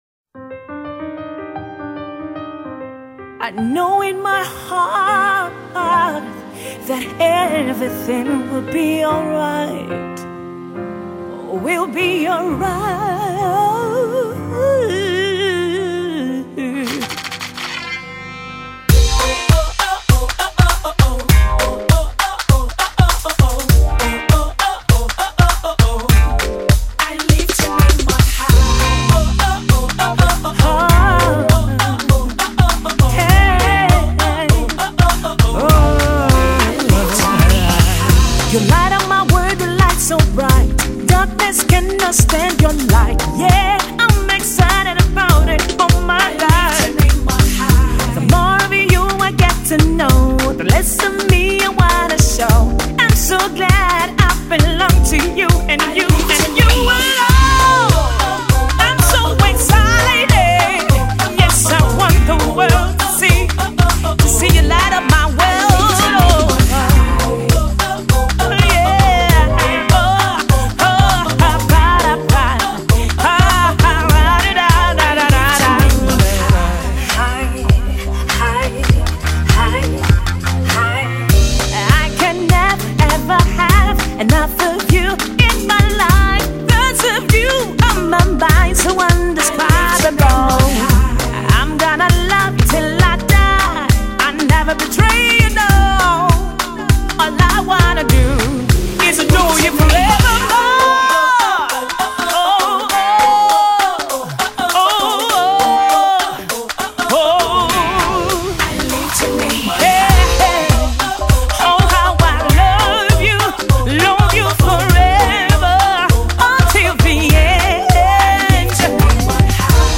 90’s influenced upbeat inspirational